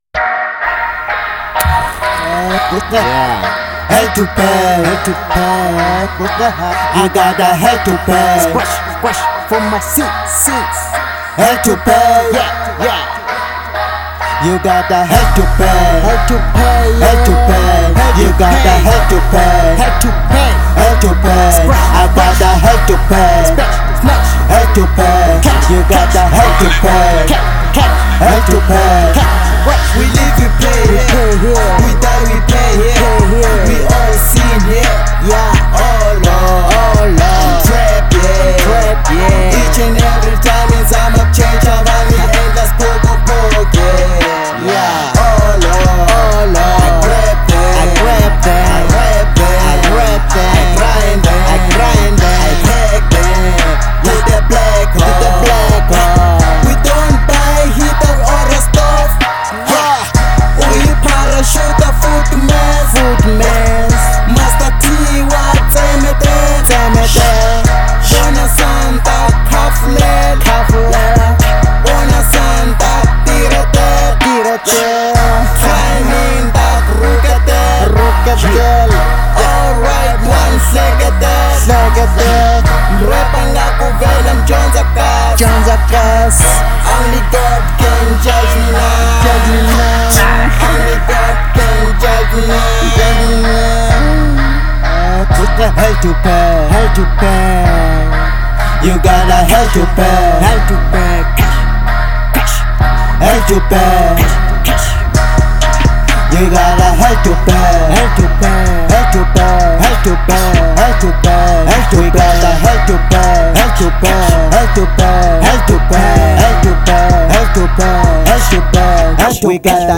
04:05 Genre : Hip Hop Size